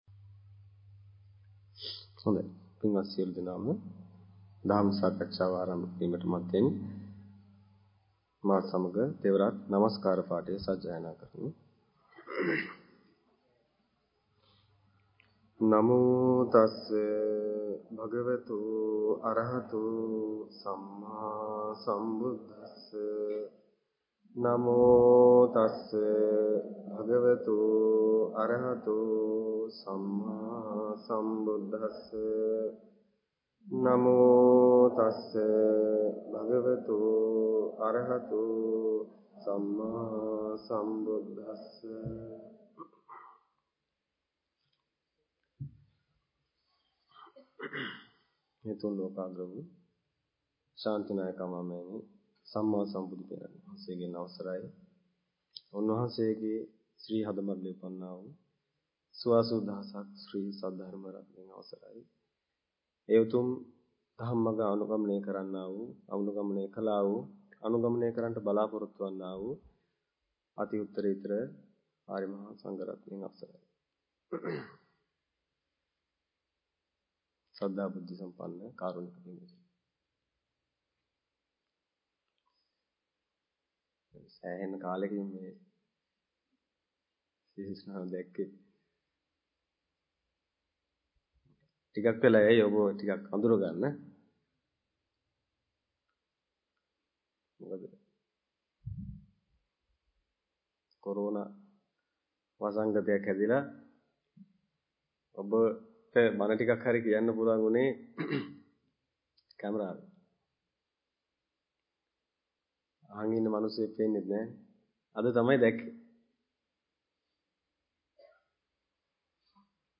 Sermon
අනුත්තරෝ - පොසොන් පෝදා උදැසන වැඩසටහන